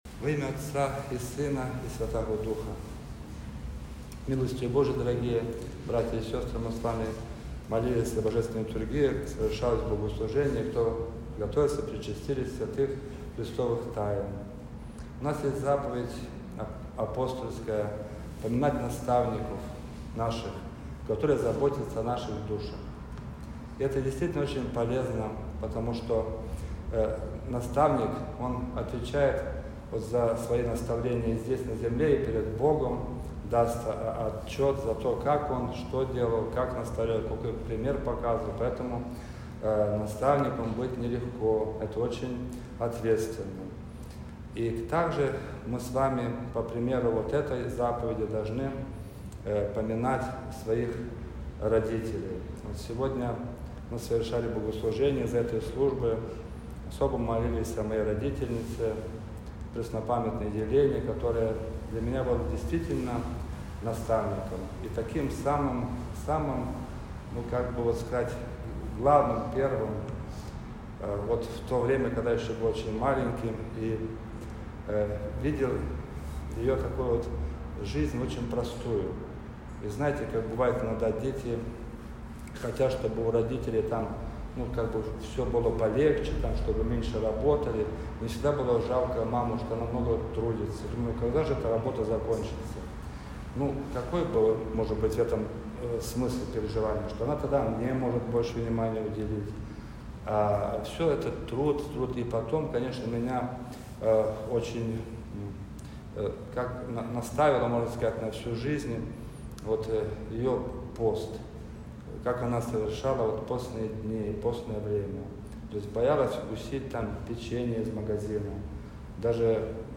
Проповедь.mp3